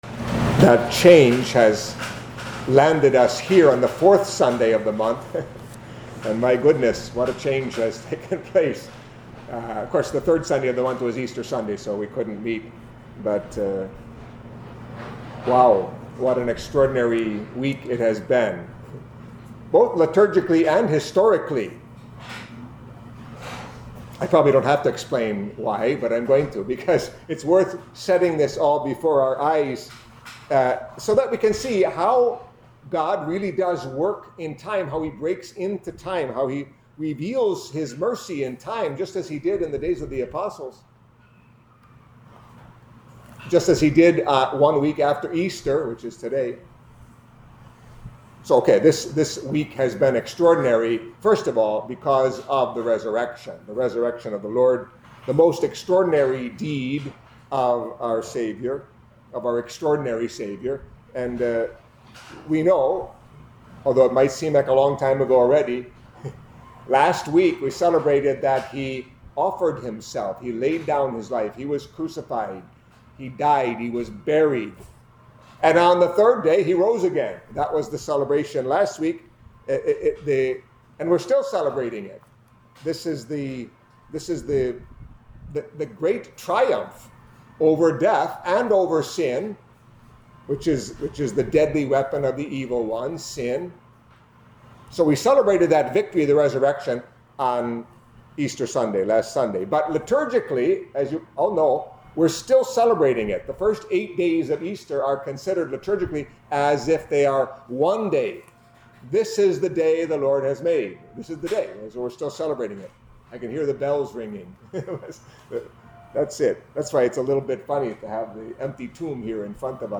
Catholic Mass homily for Second Sunday of Easter